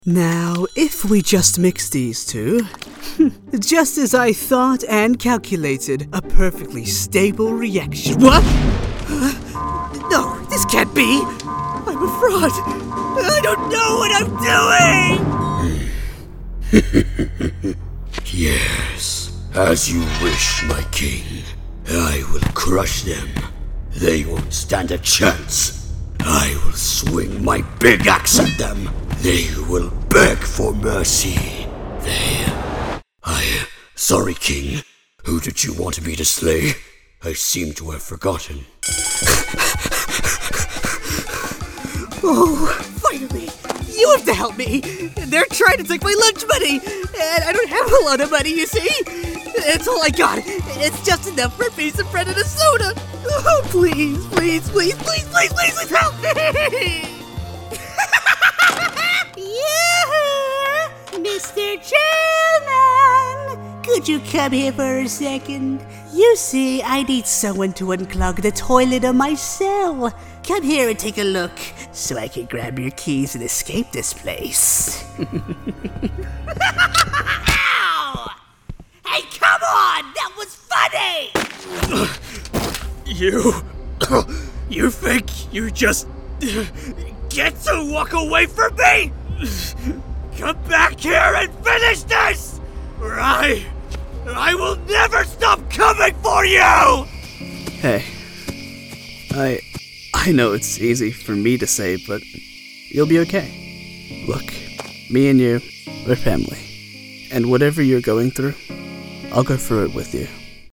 ANIMATION 🎬